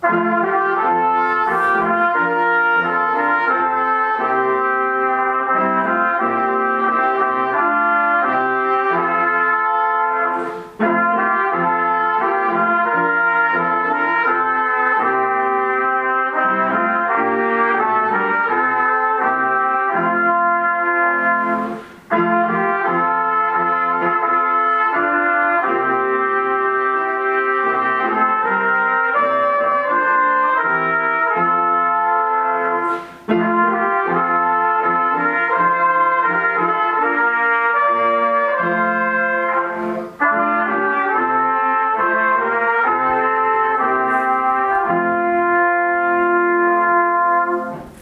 Cantique joué à la trompette et au piano !